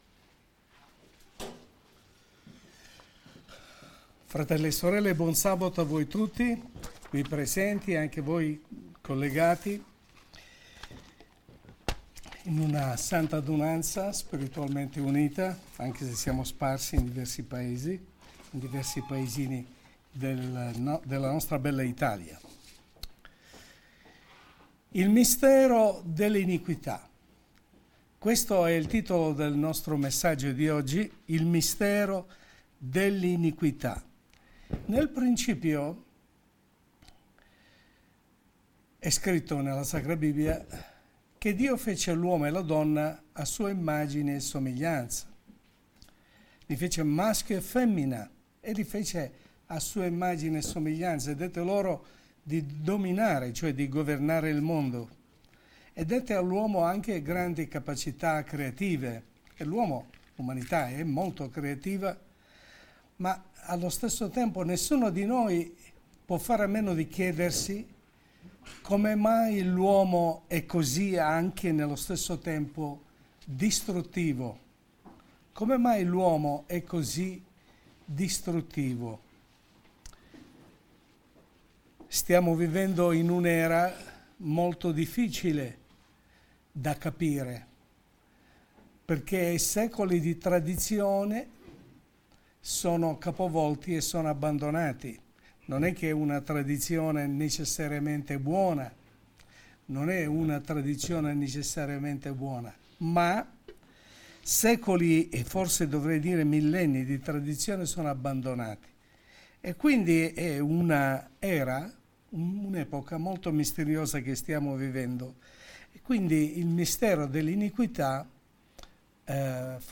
Messaggio pastorale